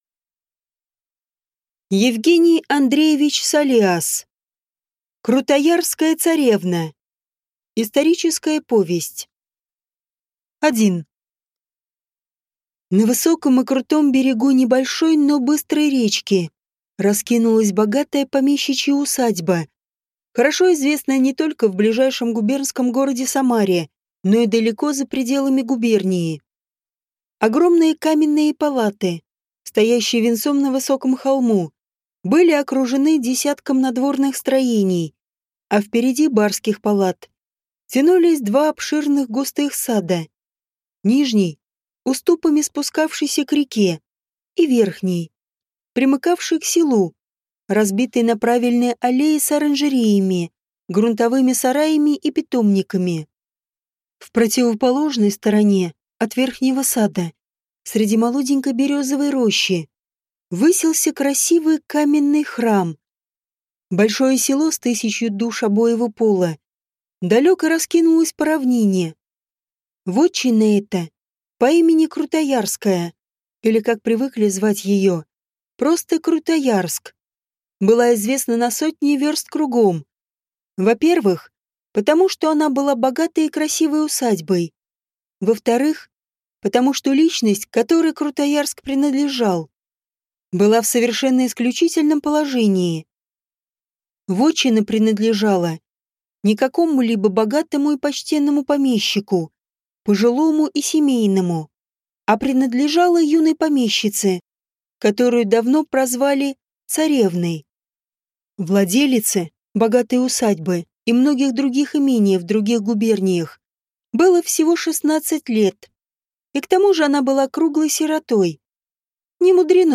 Аудиокнига Крутоярская царевна | Библиотека аудиокниг